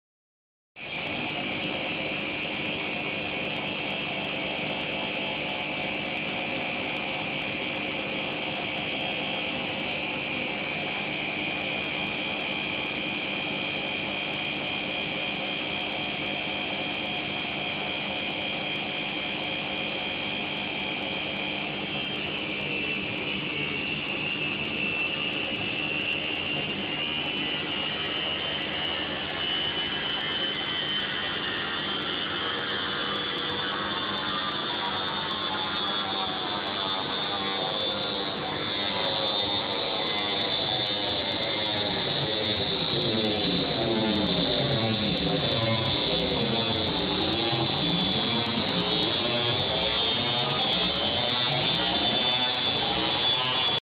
Pralni stroj
Vzel sem od Boscha WAE2437FB in poberem ven vse štiri varostne šraufe, priklopim na vodo, odtok vse skupaj, zaženem prvi program in ko zalaufa centrifuga začne glasno piskati. Sem dal še file zraven kako piska Kaj bi lahko bilo, preden kličem servis?